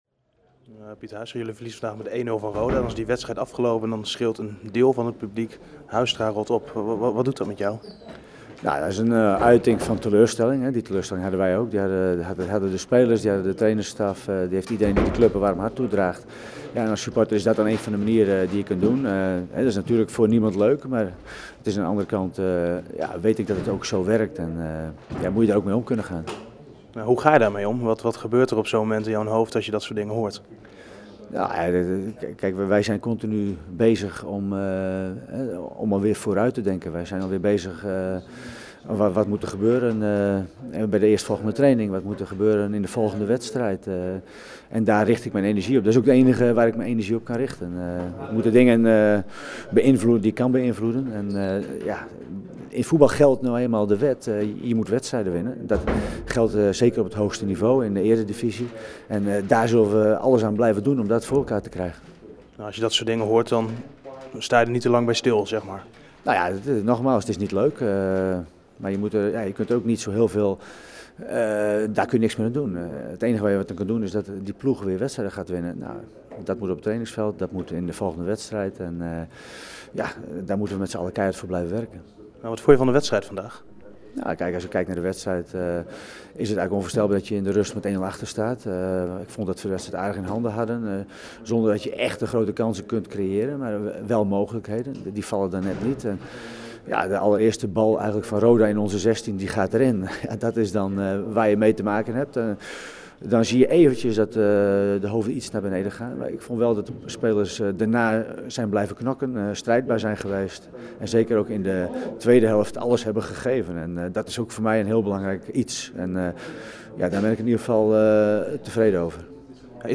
Pieter Huistra in gesprek met verslaggever